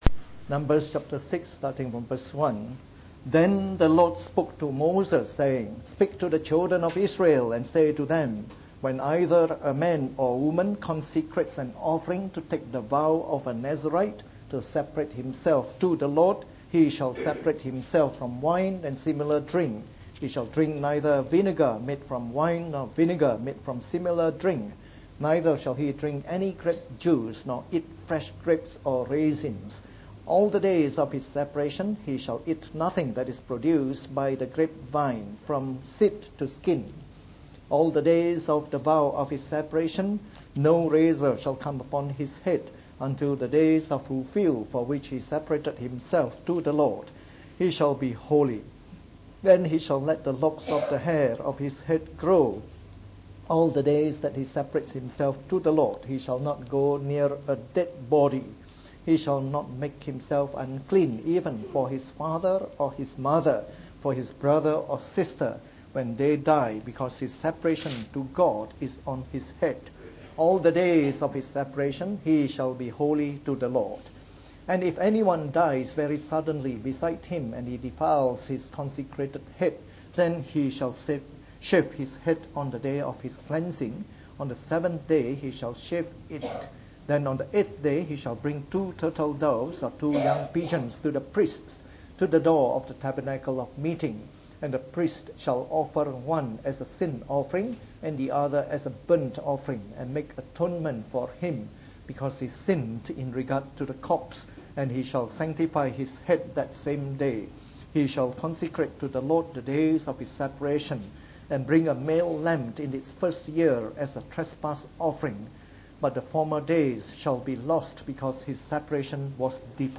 From our new series on the “Book of Numbers” delivered in the Morning Service.